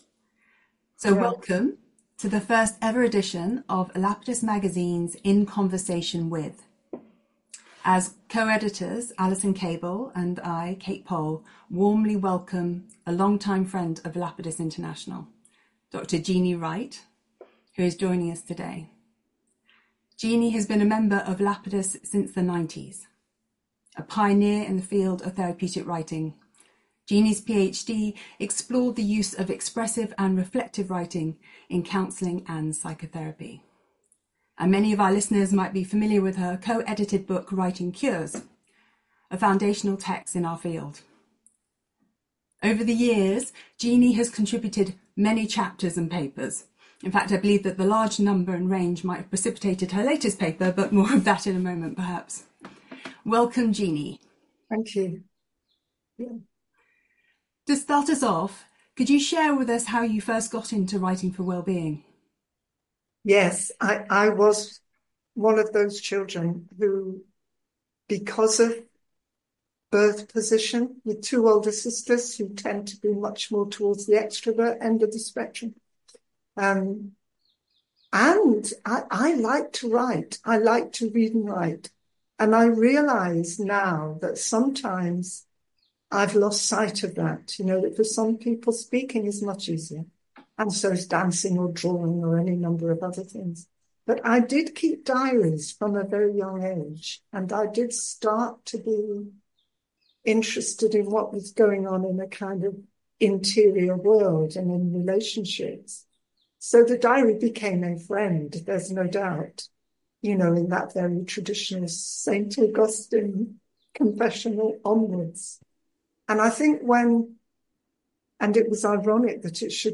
In Conversation With